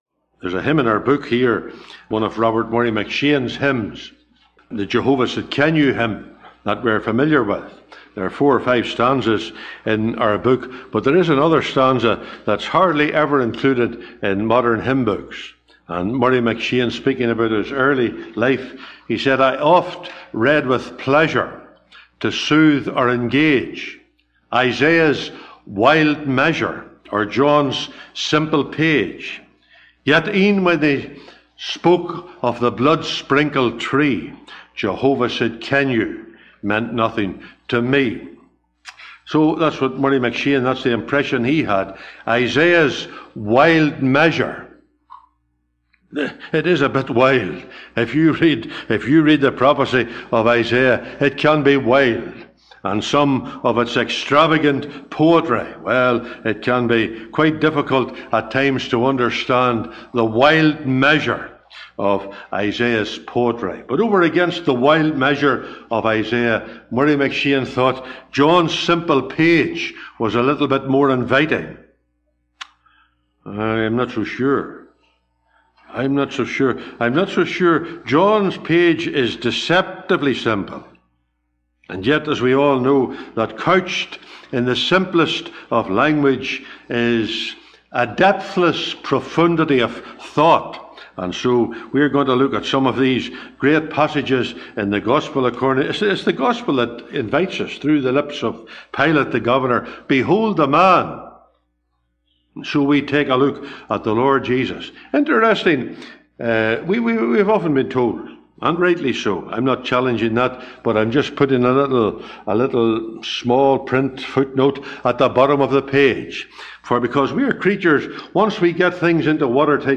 (Recorded in Stark Road Gospel Hall, 11th Sept 2024)